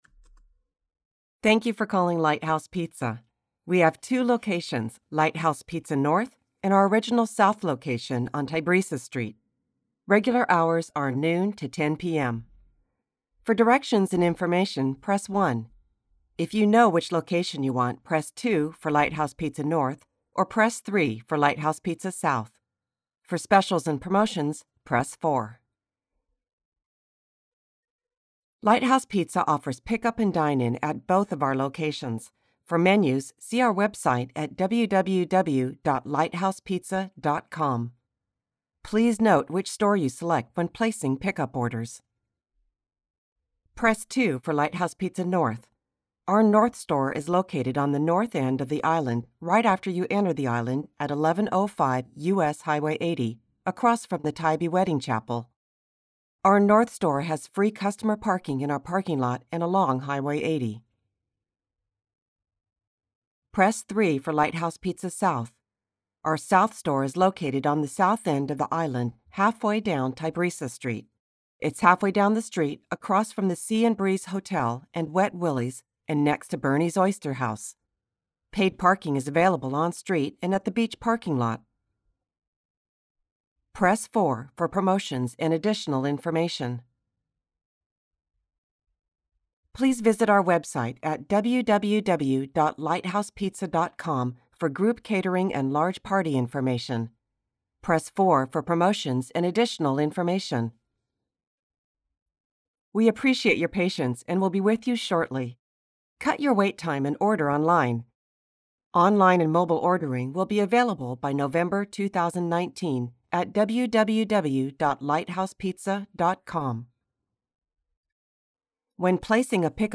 Current, versatile, engaging, rich, warm. Natural Yorkshire tones if required, as is heightened RP - BBC 1940's newsreader style.